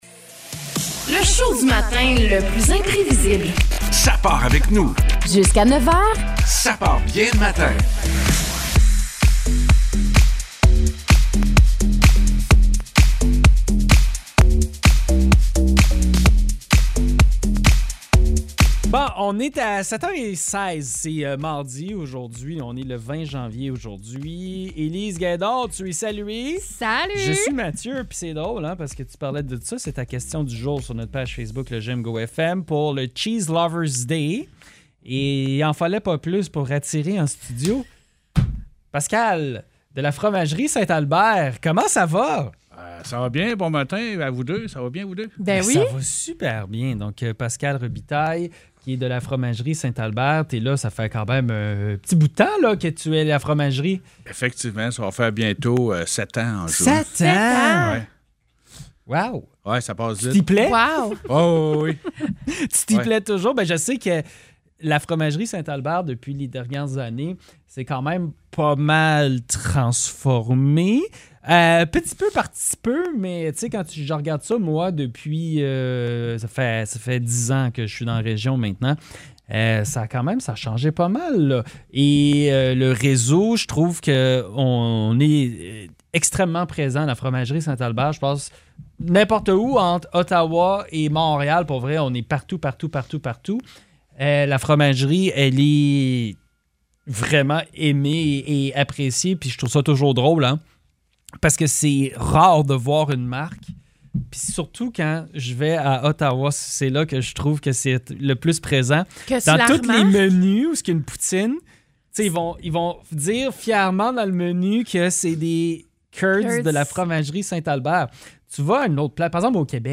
À l’occasion de la Journée nationale des amateurs de fromage (Cheese Lovers Day), nous avons reçu en studio